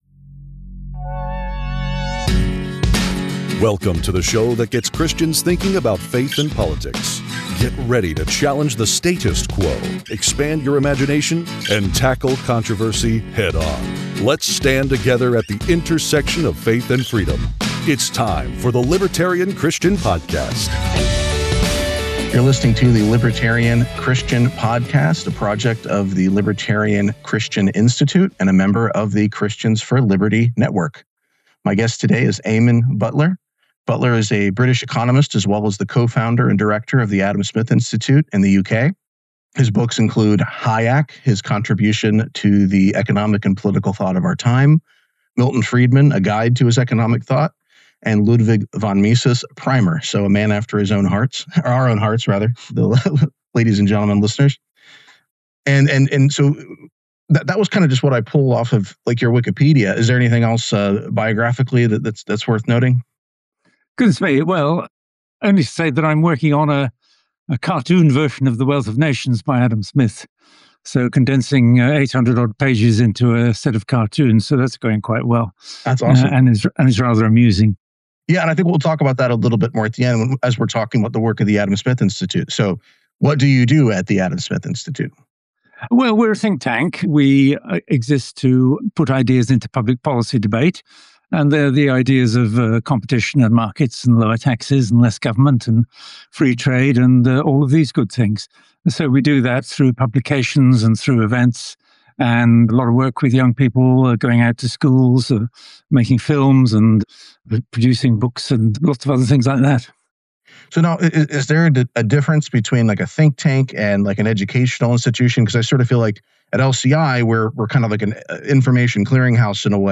The conversation explores Smith’s enduring legacy as the father of modern economics, rooted in the Scottish Enlightenment.